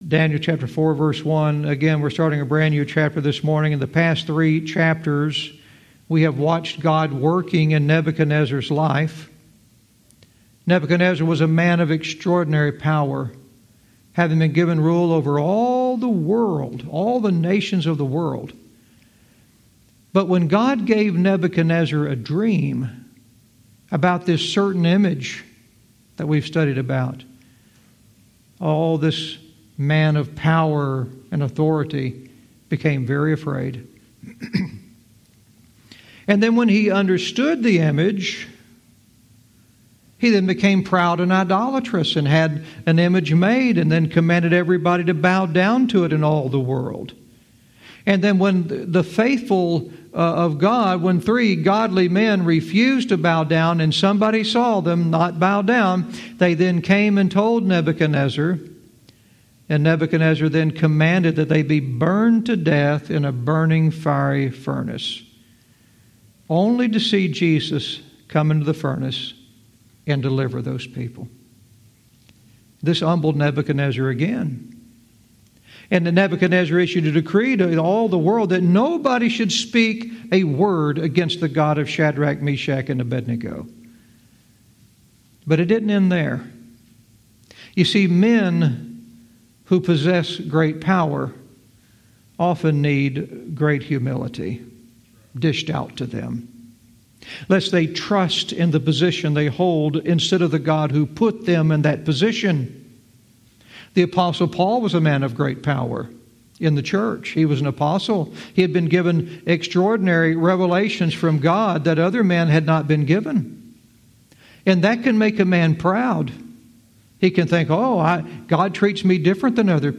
Verse by verse teaching - Daniel 4:1 "Good News to All People"